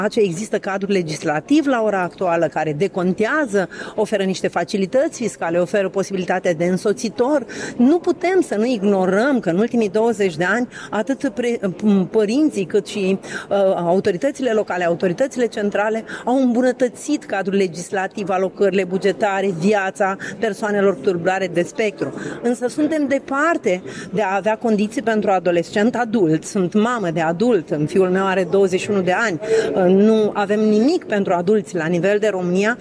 Legislația privind persoanele și copiii cu autism din România necesită completări, conform concluziilor dezbaterii organizate astăzi la Iași, de Ziua Internațională de Conștientizare a Autismului.